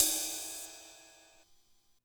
And it’s always nice to have a ride on hand: